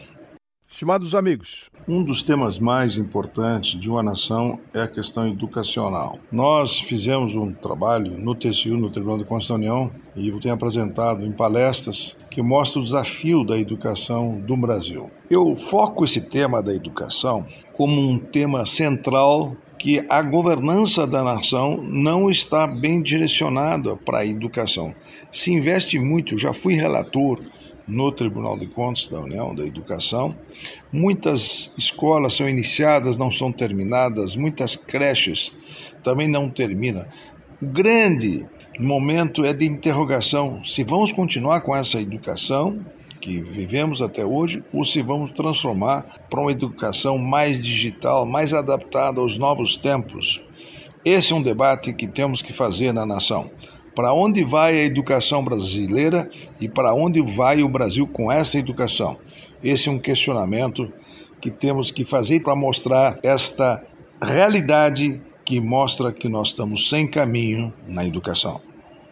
Comentário de Augusto Nardes.